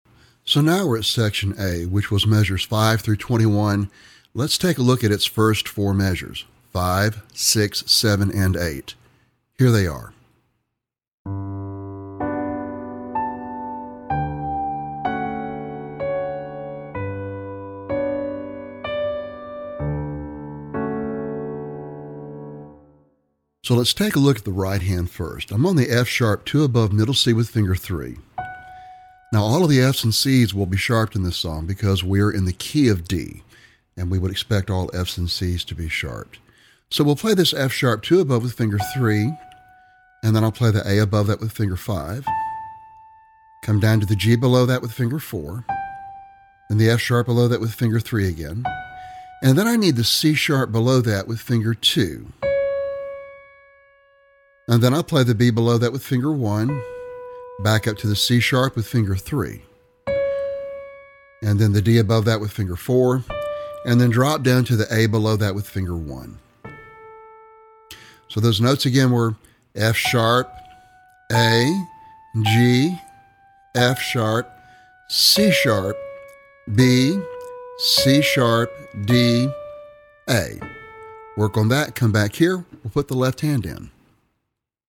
Piano Solo - Early Intermediate